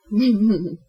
chuckle2
描述：我在观看和聆听有趣的东西的同时录制了我的声音，迫使我真正的笑声。通过这种方式，我可以拥有真实的笑片，而不是试图伪造它。
标签： 说话 说话的声音 女孩 女人
声道立体声